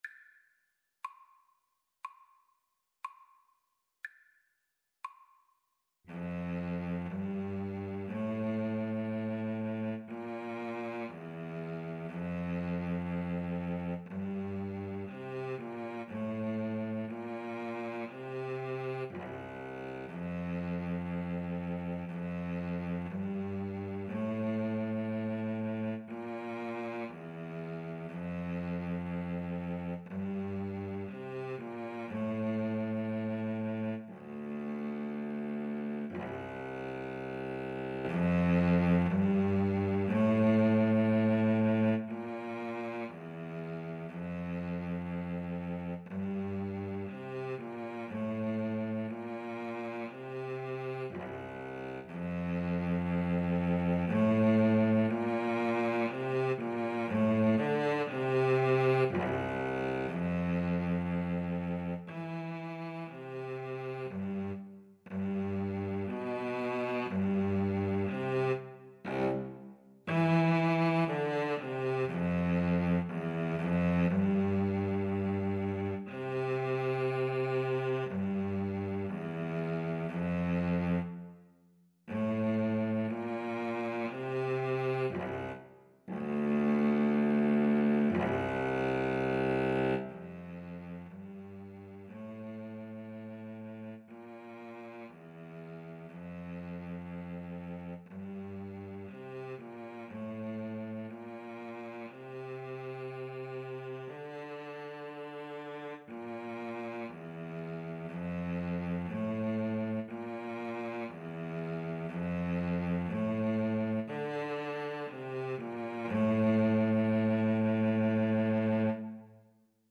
Free Sheet music for Oboe-Cello Duet
4/4 (View more 4/4 Music)
F major (Sounding Pitch) (View more F major Music for Oboe-Cello Duet )
Larghetto =60
Classical (View more Classical Oboe-Cello Duet Music)